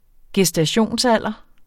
Udtale [ gεsdaˈɕoˀns- ]